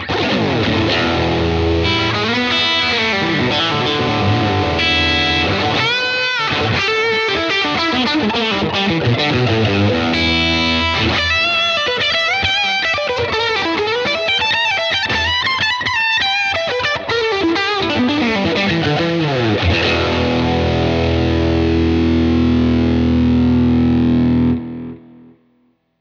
使用環境ですが完全にライン撮りです。
ギターはテレキャスターのリア【シングルコイル】です！
※ソロの方は最初からOS2を踏んでいて、ディレイも使用しています。
テレキャスター→OS2→エルキャビスタン→Dream65
BOSS OS2 TL SOLO